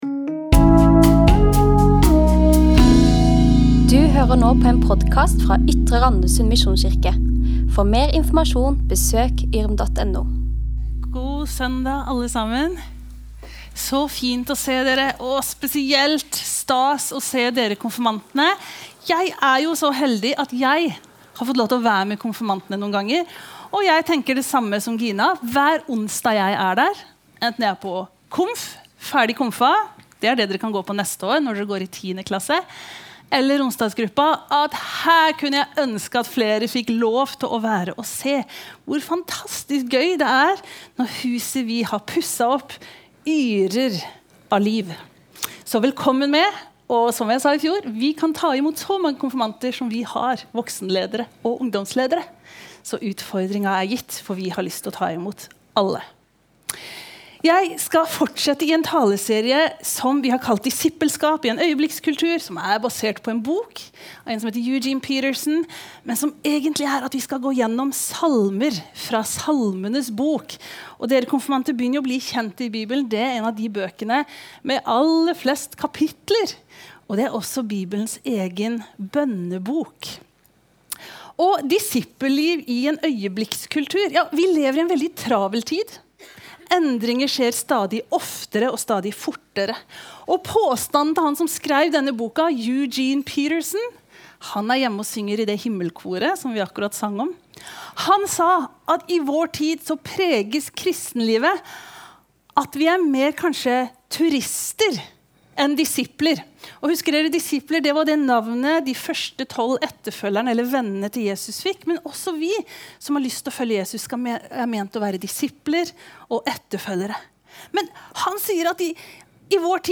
Listen to Menigheten som familie - 15. mars 2026 from Podcast og taler fra Ytre Randesund misjonskirke.